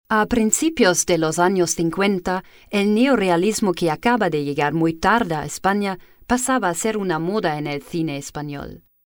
Guten Tag, Hello, Bonjour, Buenos Dias ! meine Stimme ist warm, verbindlich und freundlich, perfekt für Ihr Voice Over, Industriefilm/Doku.
deutsch - englische (uk) Sprecherin.
Sprechprobe: Sonstiges (Muttersprache):